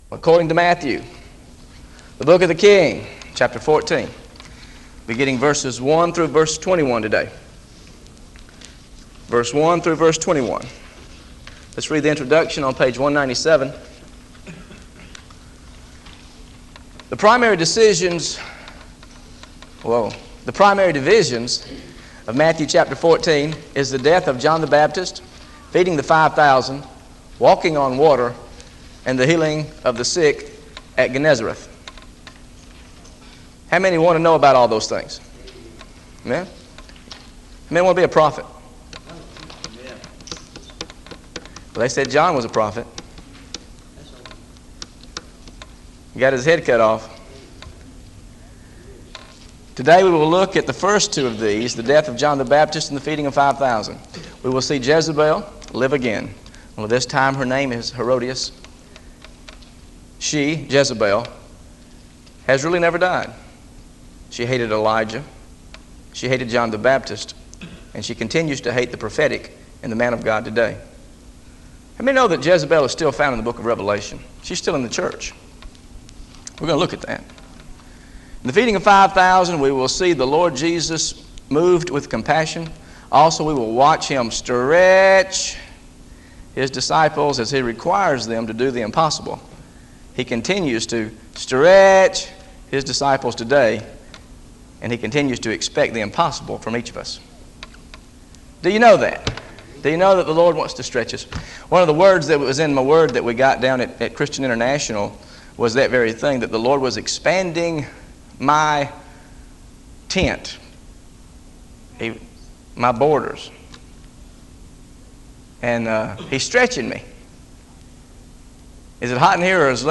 GOSPEL OF MATTHEW BIBLE STUDY SERIES This study of Matthew: Matthew 14 1-21 How to Face Jezebel and Feed the Multitude is part of a verse-by-verse teaching series through the Gospel of Matthew.